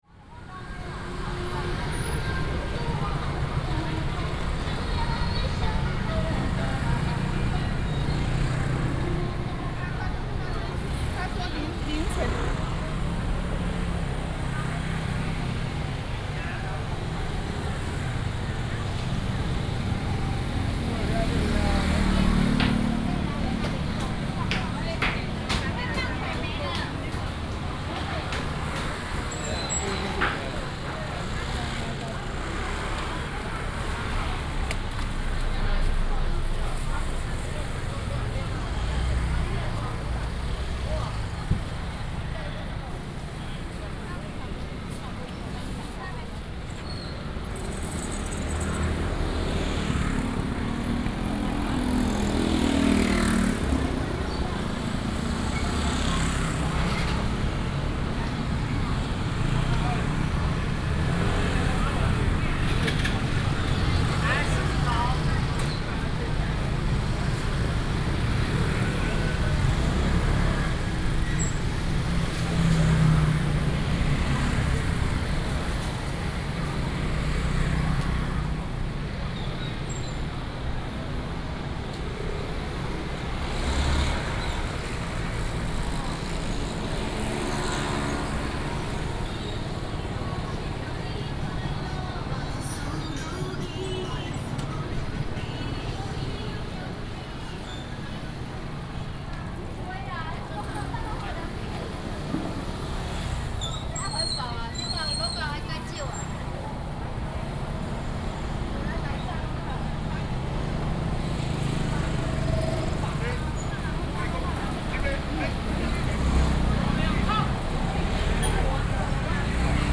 Walking in the Traditional market block,Traffic sound,,Binaural recordings ,Best with Headphone
Sony PCM D100+ Soundman OKM II